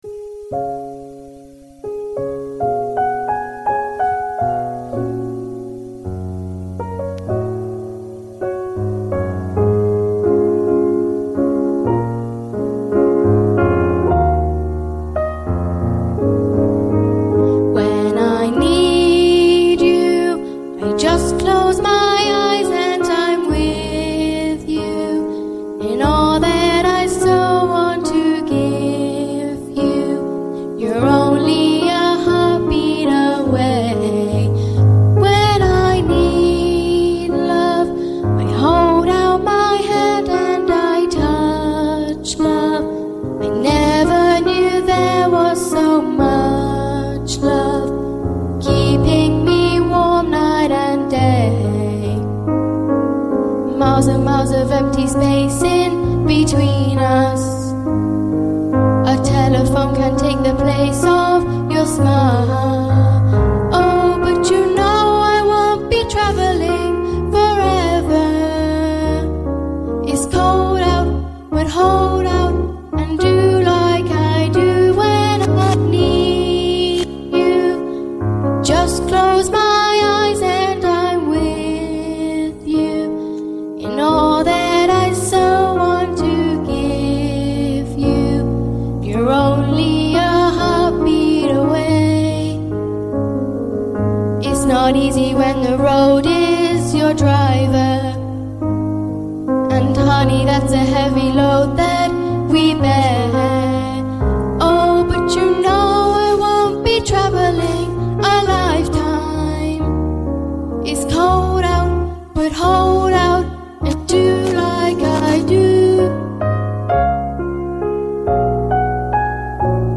St Andrews Players - Live!